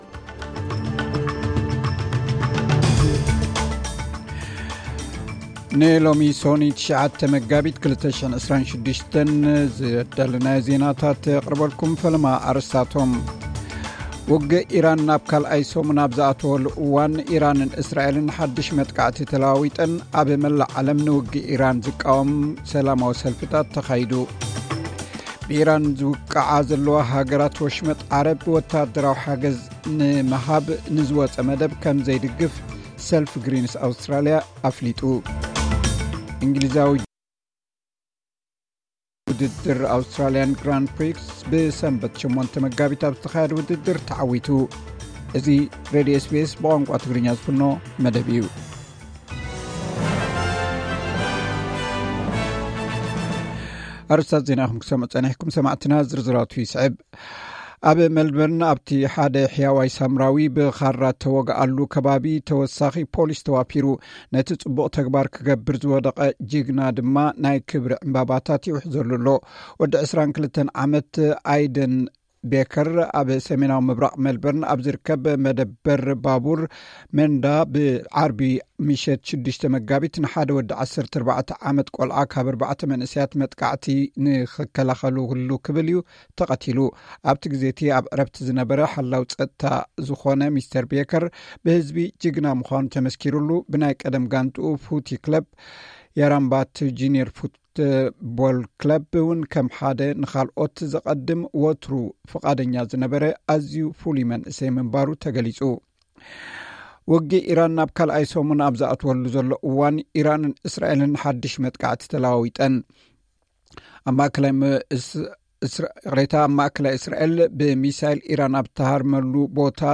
ዕለታዊ ዜና SBS ትግርኛ (09 መጋቢት 2026)